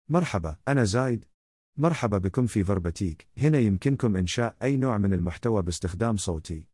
ZaydMale Gulf Arabic AI voice
Zayd is a male AI voice for Gulf Arabic.
Voice sample
Listen to Zayd's male Gulf Arabic voice.
Zayd delivers clear pronunciation with authentic Gulf Arabic intonation, making your content sound professionally produced.